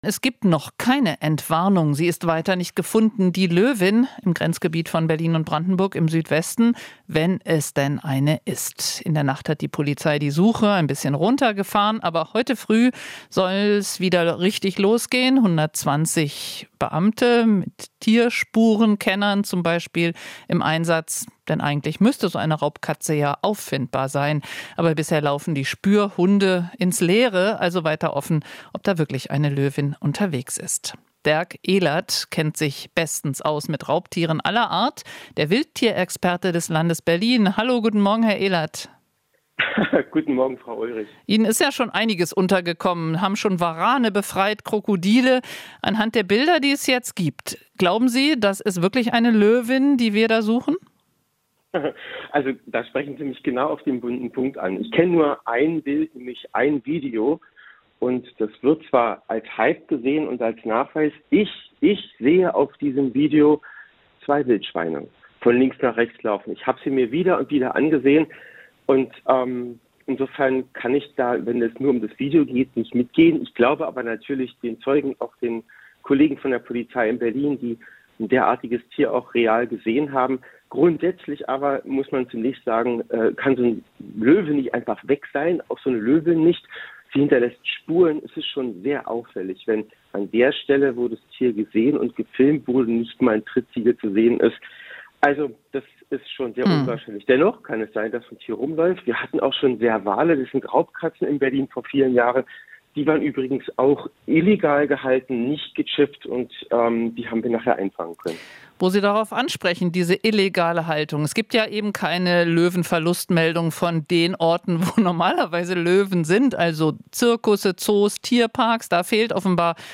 Interview - Wildtierexperte zweifelt: "Eine Löwin löst sich nicht in Luft auf"